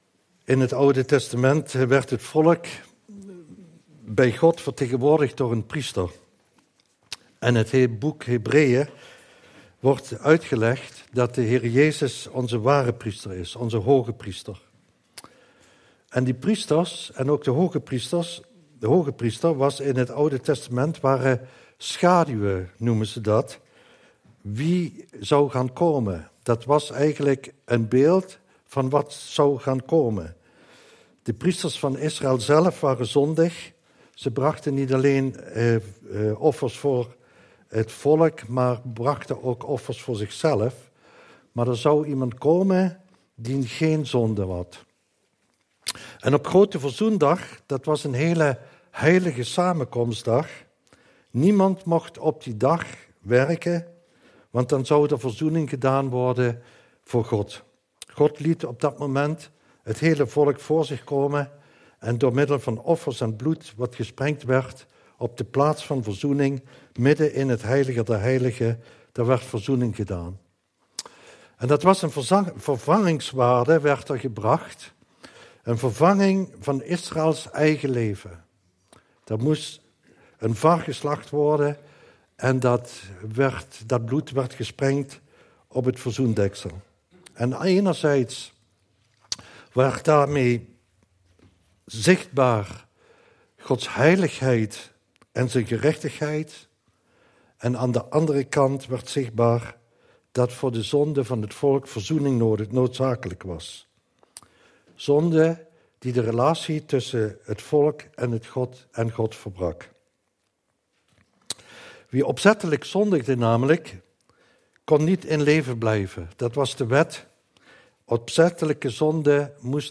Dienstsoort: Eredienst